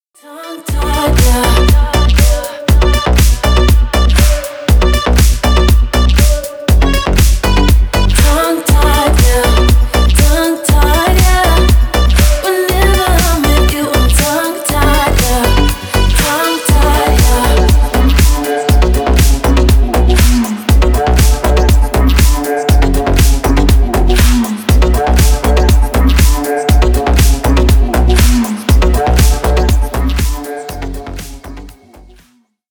# Танцевальные
# клубные